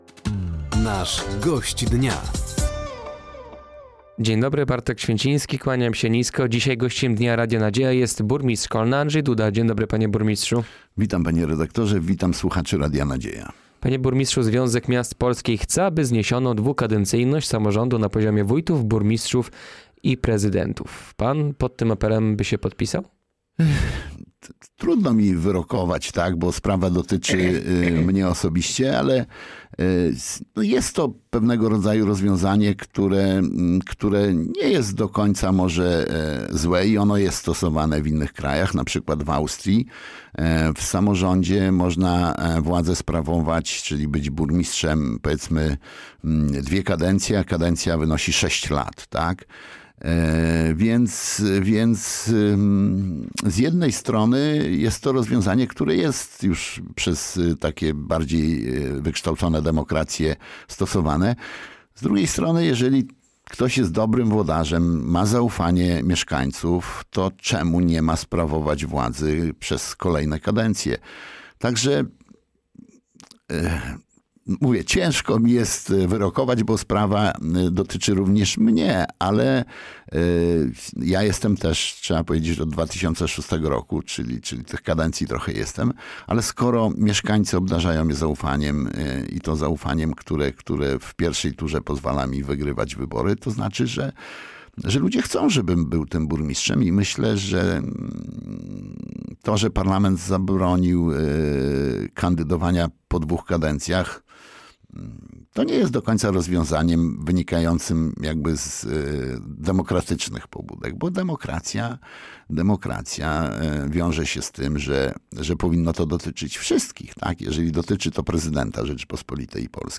Gościem Dnia Radia Nadzieja był burmistrz Kolna Andrzej Duda. Tematem rozmowy było finansowanie samorządów i przyszłoroczny budżet miasta.